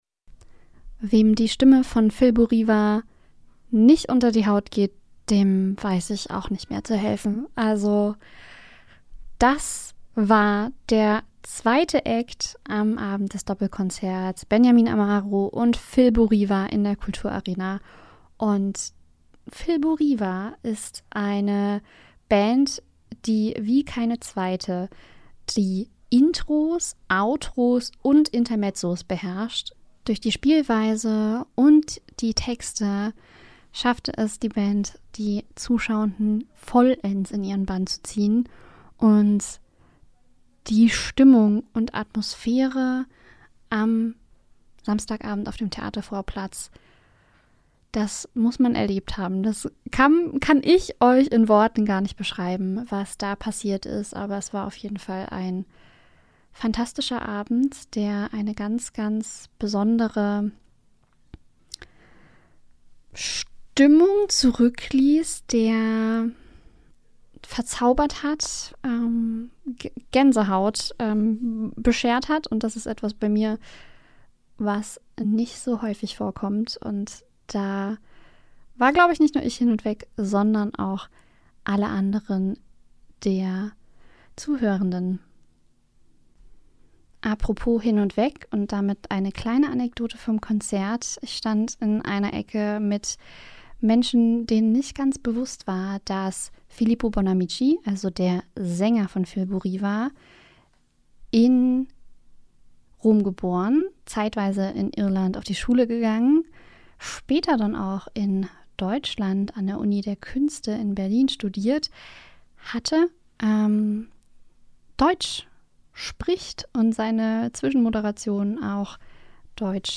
Liveberichterstattung vom Theatervorplatz.
RadioArena Rezension Filmarena